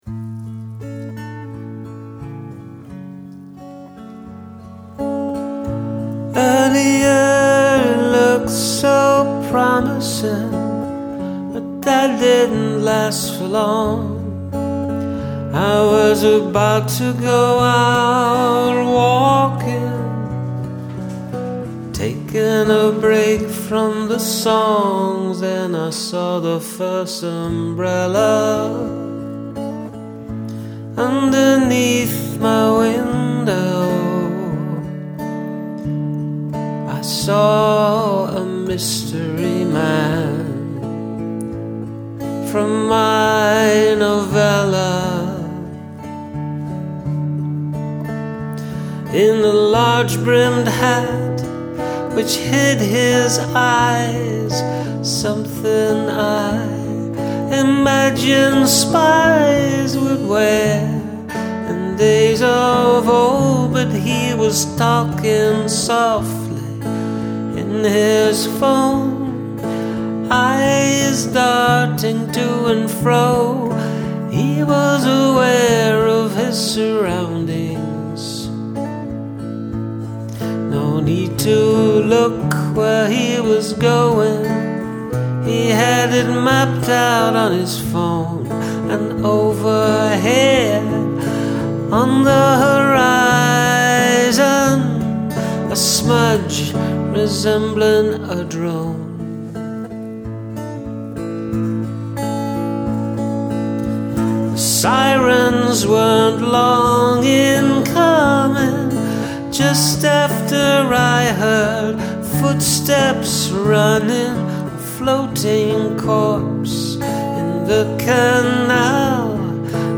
A quick one-take thingy